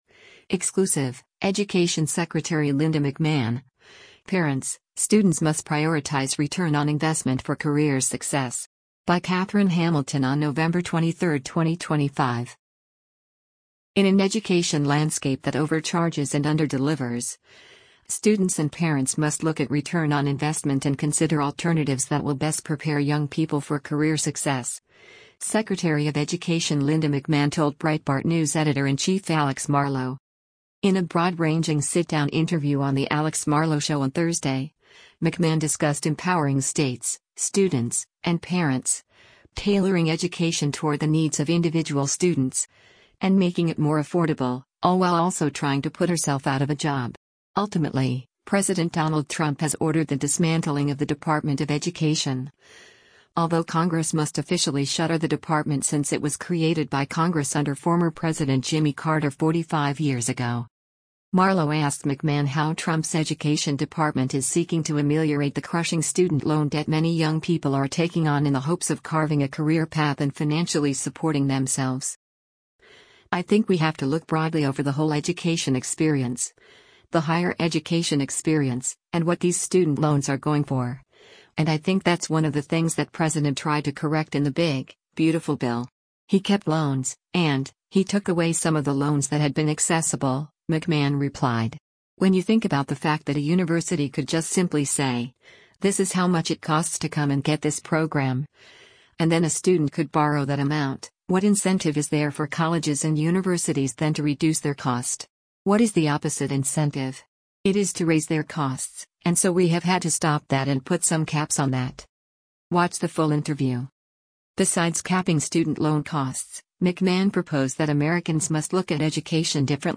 In a broad-ranging sit-down interview on The Alex Marlow Show on Thursday, McMahon discussed empowering states, students, and parents, tailoring education toward the needs of individual students, and making it more affordable — all while also trying to put herself out of a job.